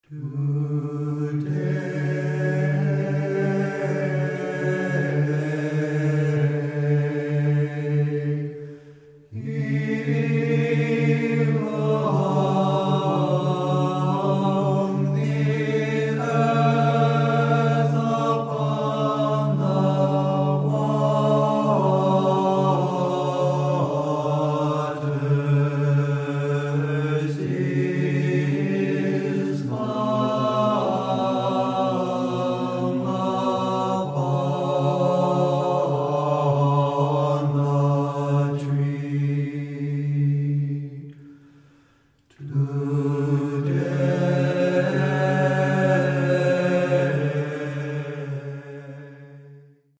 Byzantine--Tone 6